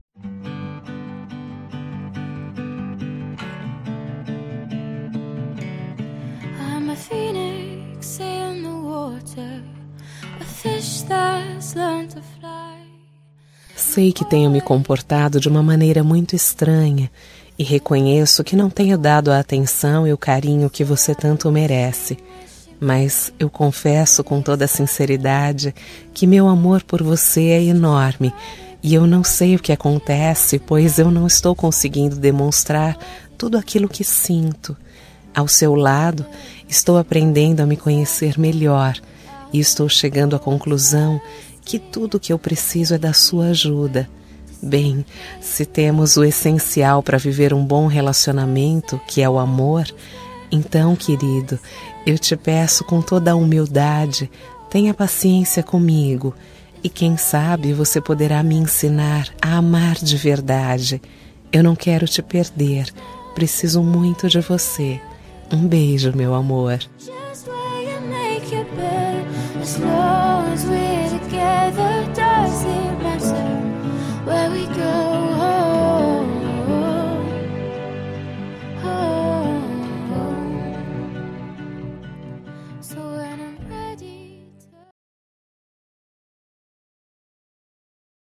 Telemensagem de Desculpas – Voz Feminina – Cód: 372